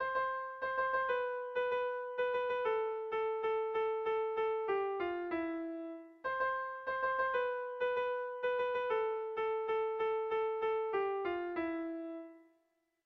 Kontakizunezkoa
Ataun < Goierri < Gipuzkoa < Euskal Herria
Lauko handia (hg) / Bi puntuko handia (ip)
AA